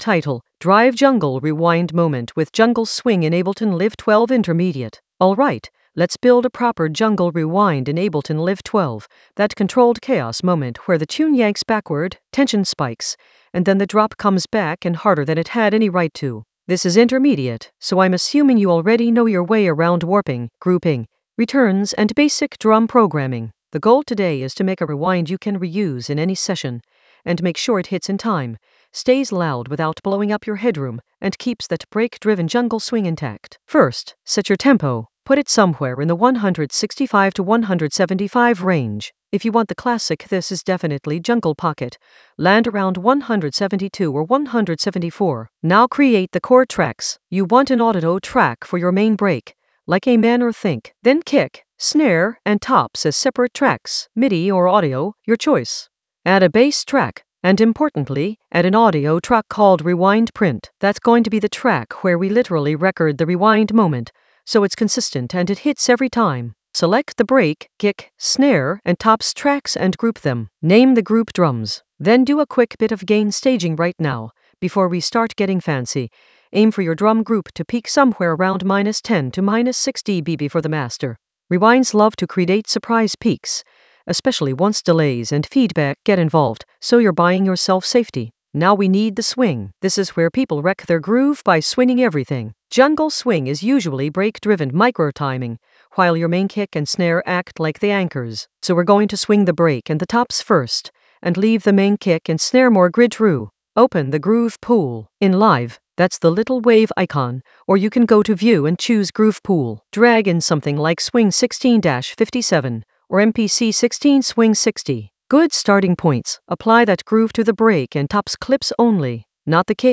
Narrated lesson audio
The voice track includes the tutorial plus extra teacher commentary.
An AI-generated intermediate Ableton lesson focused on Drive jungle rewind moment with jungle swing in Ableton Live 12 in the Sound Design area of drum and bass production.